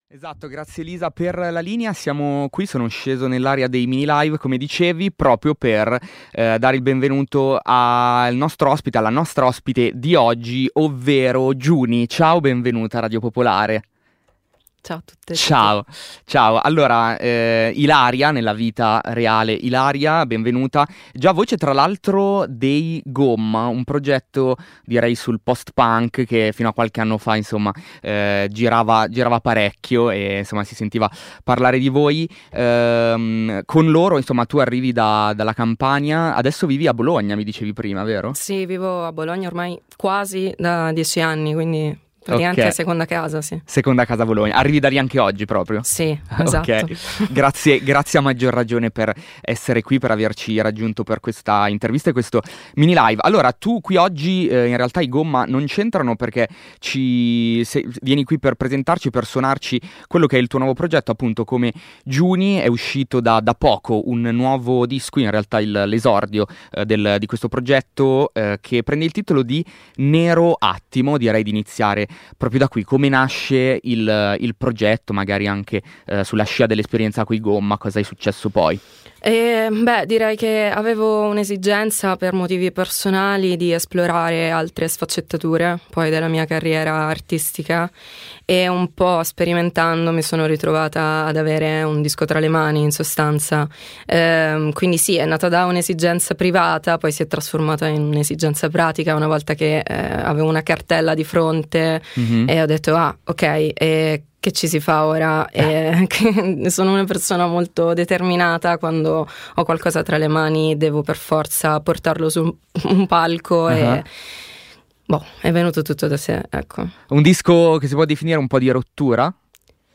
Ascolta l’intervista e il MiniLive